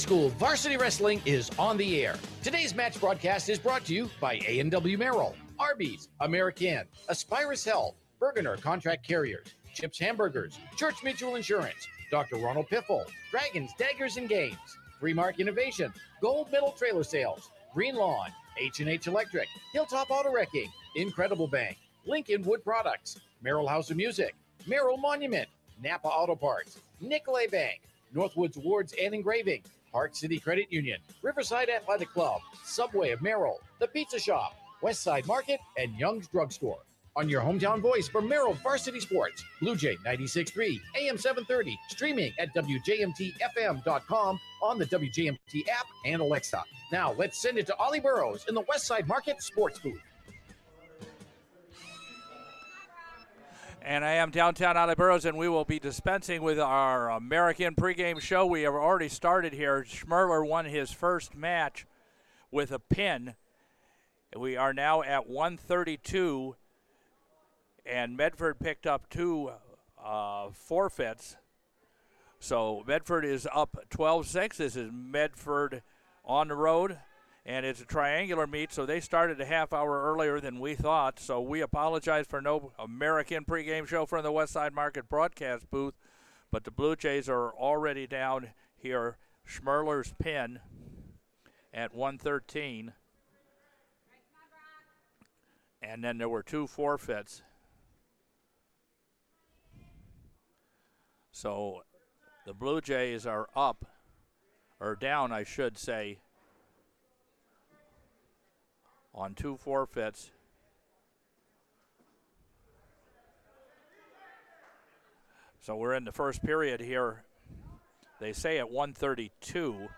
1-29-26 – Merrill v Medford Boys Wrestling